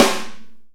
soft-hitclap.wav